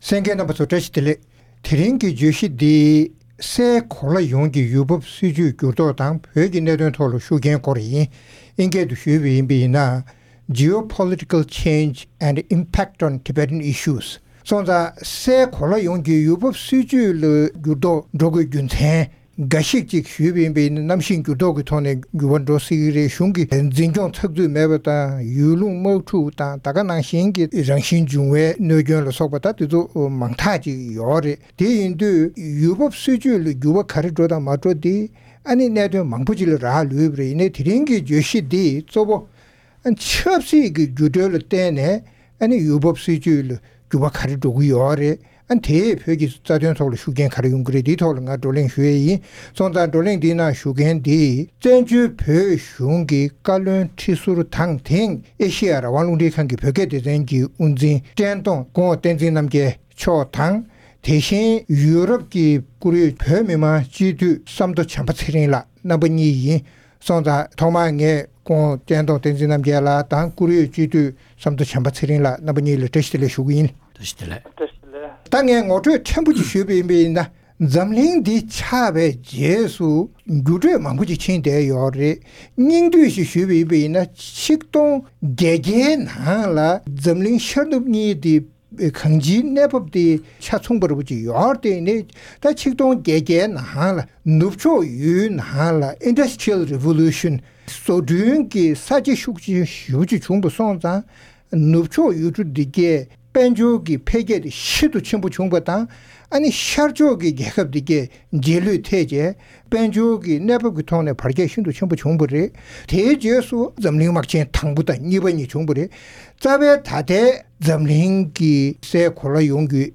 སའི་གོ་ལ་ཡོངས་ཀྱི་ཡུལ་བབ་སྲིད་བྱུས་ལ་འགྱུར་ལྡོག་དེས་བོད་དོན་ལ་ཤུགས་རྐྱེན་ཇི་ཐེབས་སོགས་ཀྱི་ཐད་བགྲོ་གླེང༌།